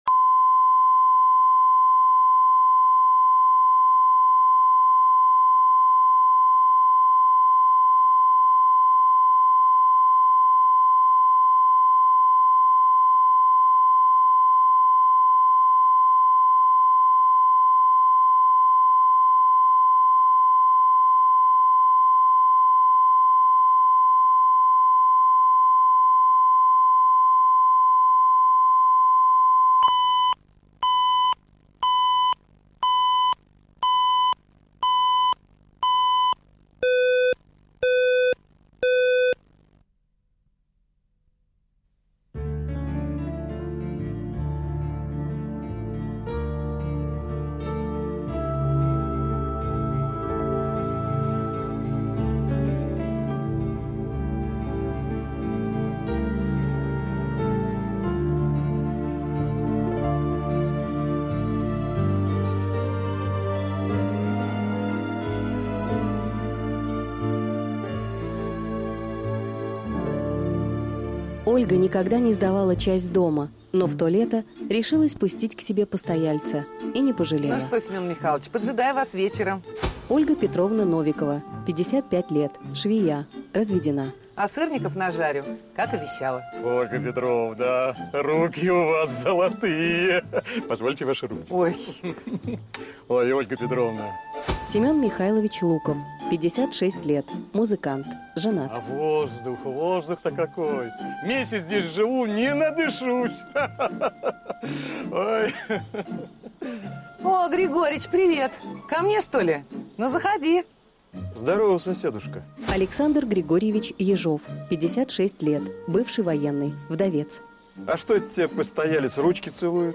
Аудиокнига Невесты Григорьича | Библиотека аудиокниг
Aудиокнига Невесты Григорьича Автор Александр Левин.